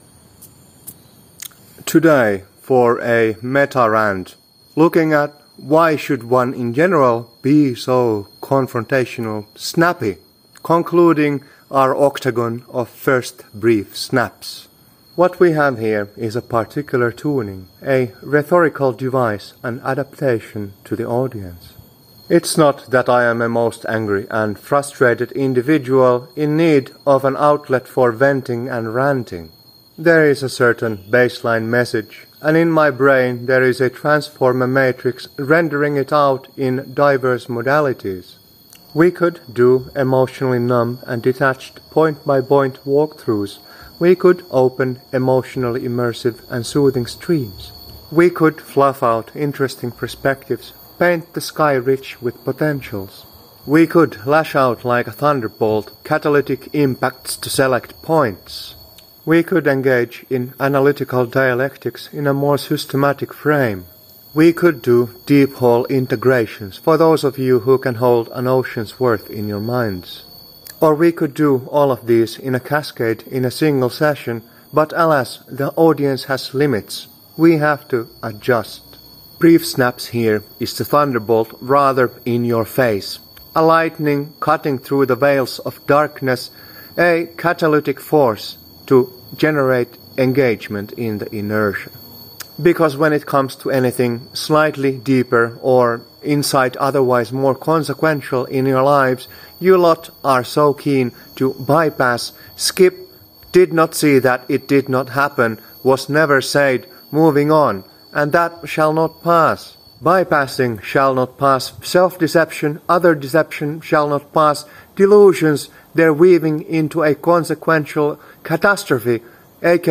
Strategy Meta-Rant × What All Shall Not Pass! (BZ008)